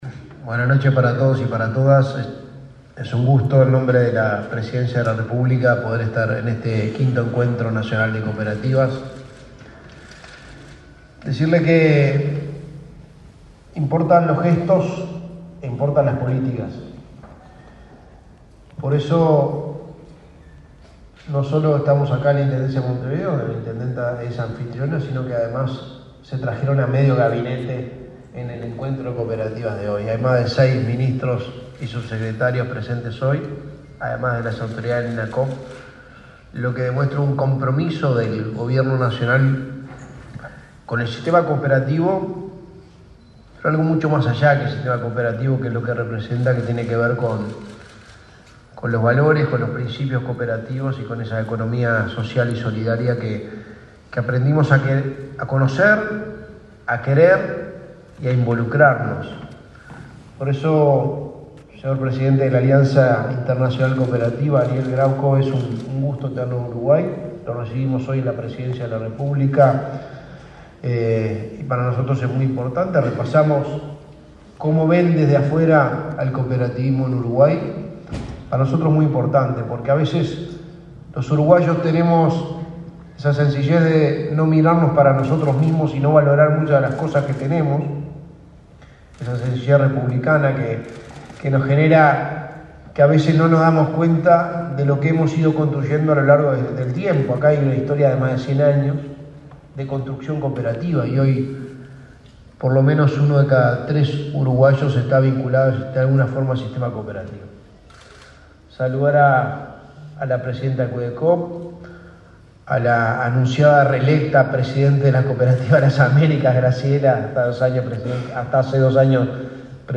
Conferencia de prensa por Encuentro Nacional de Cooperativas
Conferencia de prensa por Encuentro Nacional de Cooperativas 19/08/2022 Compartir Facebook X Copiar enlace WhatsApp LinkedIn Este 18 de agosto, se realizó la inauguración 5.° Encuentro Nacional de Cooperativas: A 20 Años de la Recomendación 193 de la OIT para la Promoción de las Cooperativas. Participaron en la conferencia el secretario de la Presidencia, Álvaro Delgado; el ministro de Trabajo y Seguridad Social, Pablo Mieres; el ministro de Ambiente, Adrián Peña, y la vicepresidenta de la República, Beatriz Argimón.